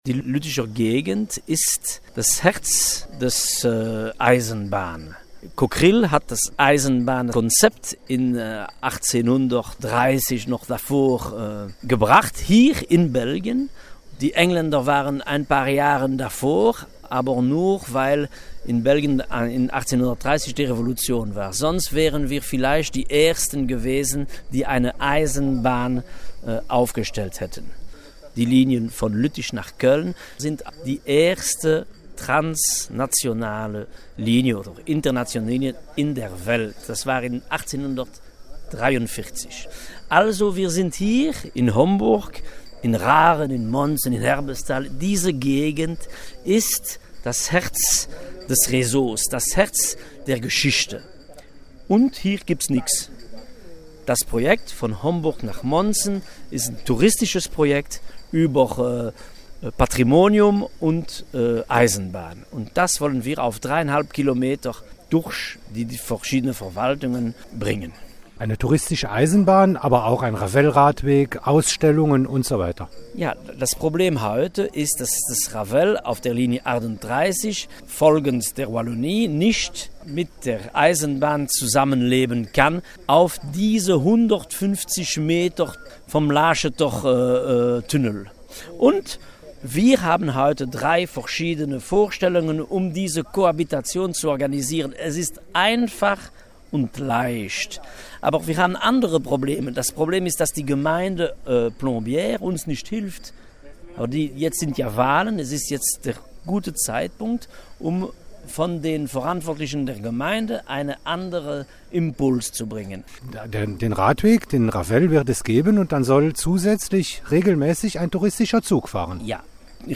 So klingt jedenfalls ein leidenschaftlicher Eisenbahner!